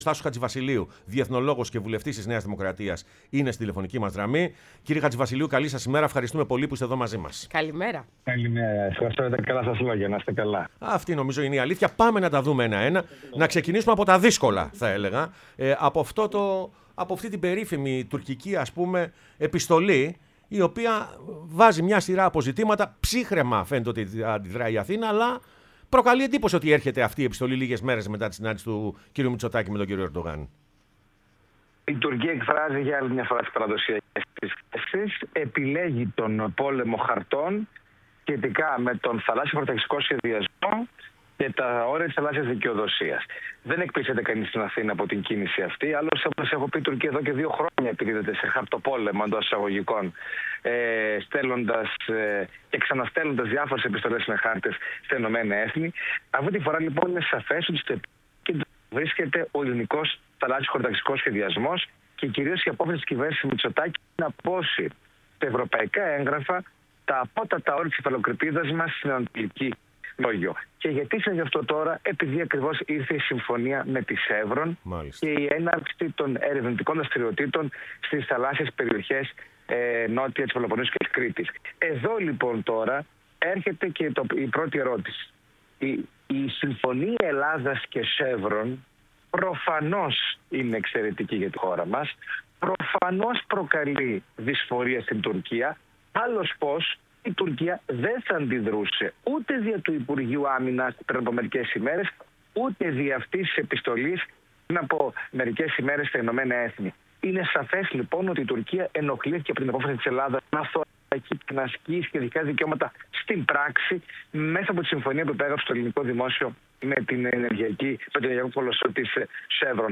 Ο Τάσος Χατζηβασιλείου, διεθνολόγος και βουλευτής ΝΔ  μίλησε στην εκπομπή «Πρωινές Διαδρομές»